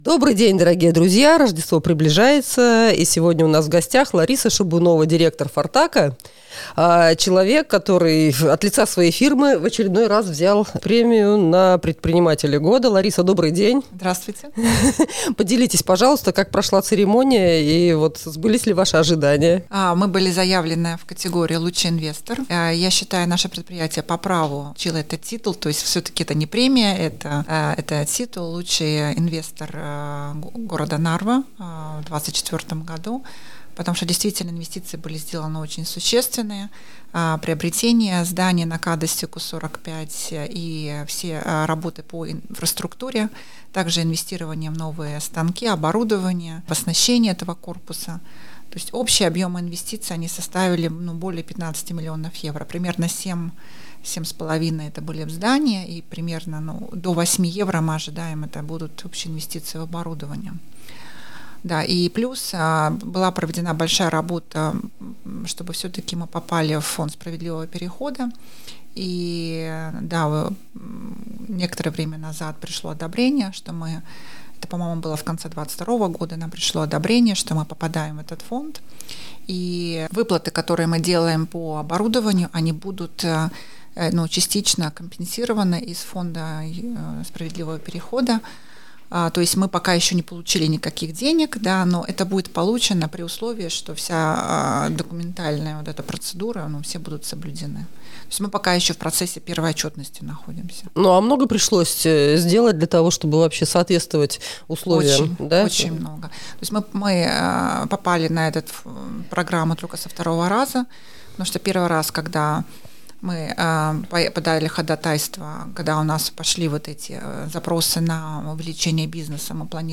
гость нашей студии.